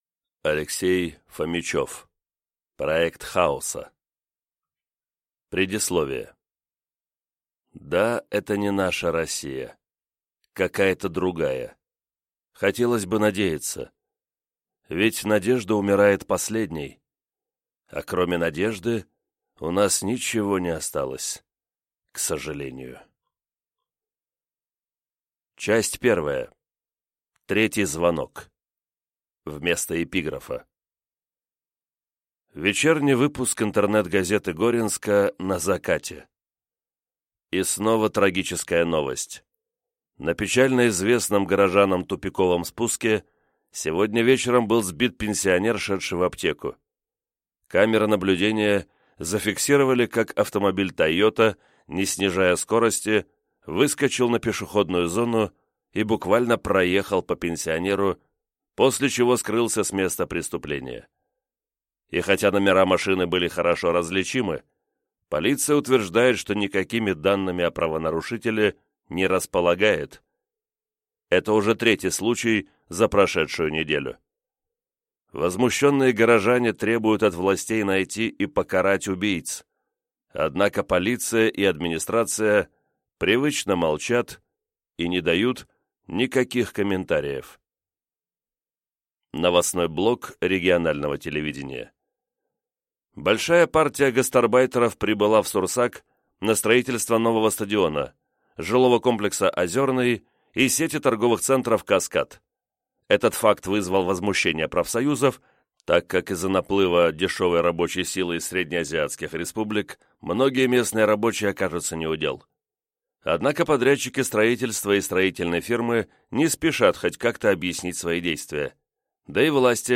Аудиокнига Проект хаоса | Библиотека аудиокниг